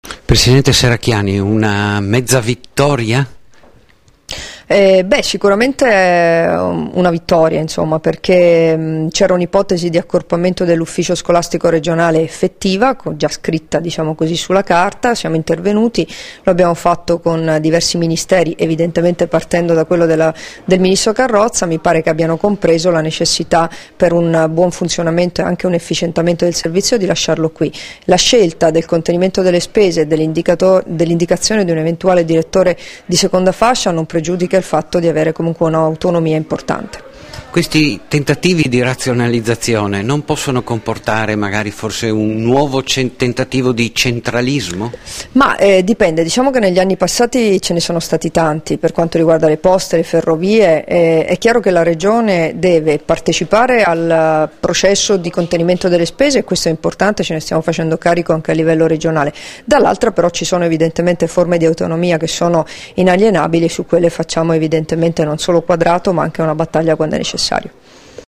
Dichiarazioni di Debora Serracchiani (Formato MP3) [1094KB]
sull'ipotesi di accorpamento a Venezia dell'USR-Ufficio Scolastico Regionale del Friuli Venezia Giulia, rilasciate a Udine il 16 novembre 2013